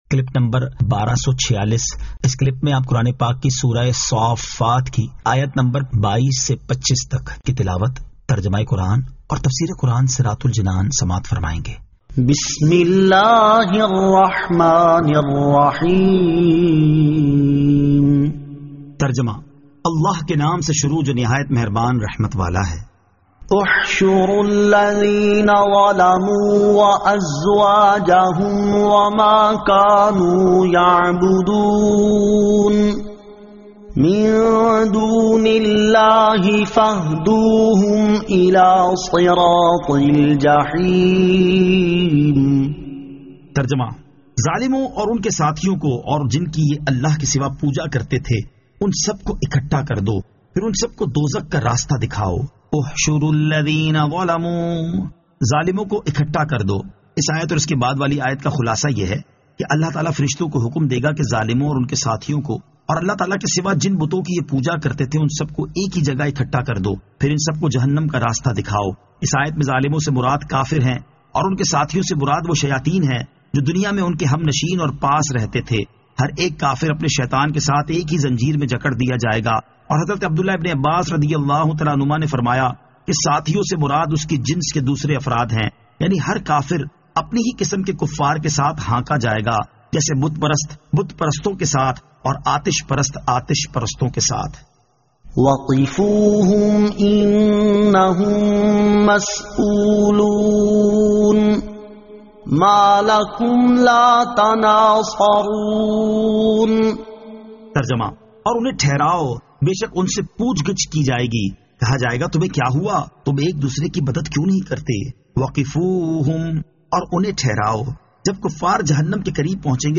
Surah As-Saaffat 22 To 25 Tilawat , Tarjama , Tafseer